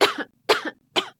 Add cough sound effects
cough_w_1.ogg